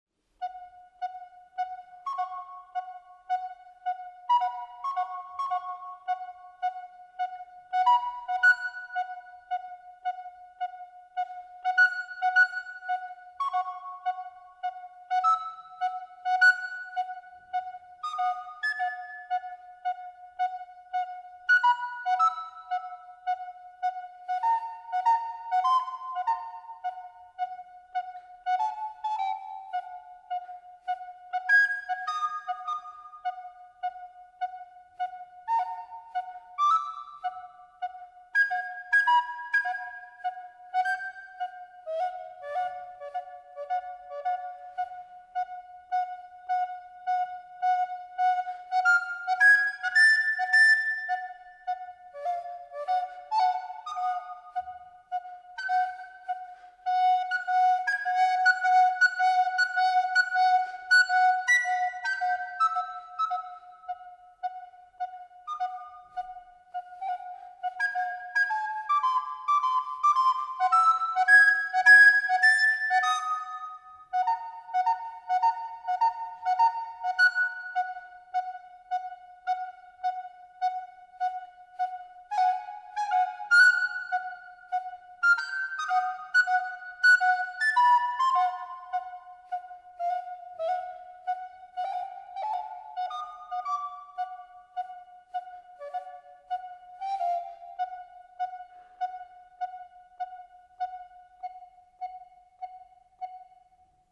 Contemporary classical. Recorder soloist